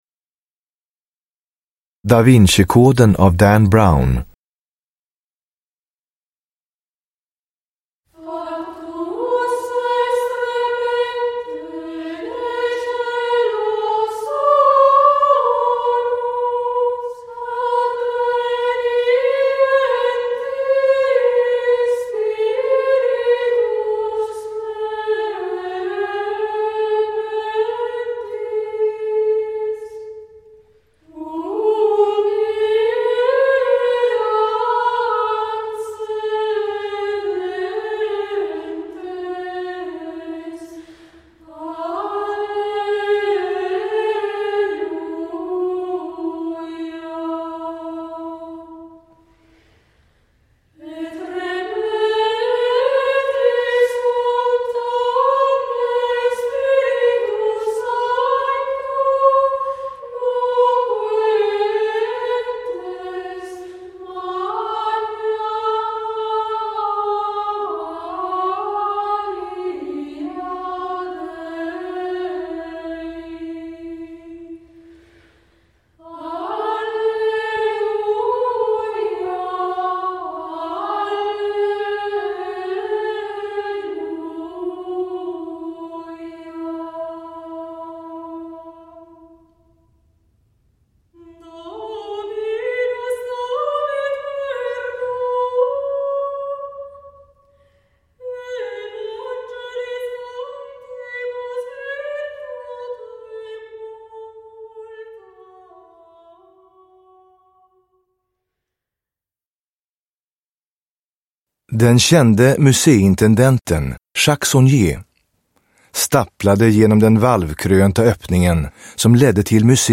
Da Vinci-koden – Ljudbok – Laddas ner